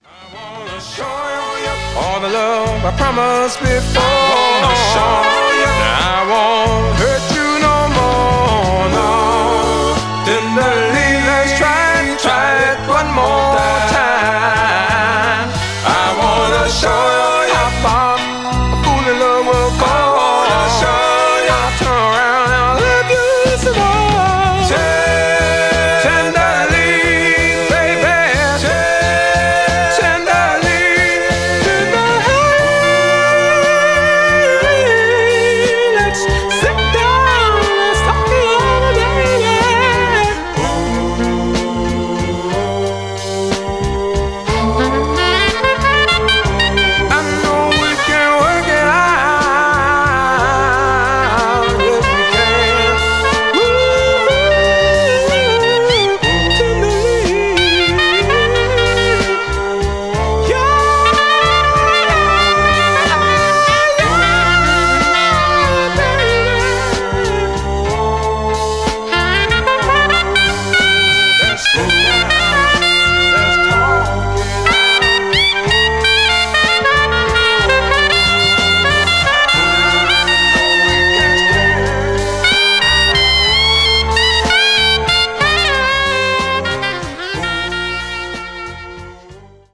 ジャンル(スタイル) SOUL / FUNK / DISCO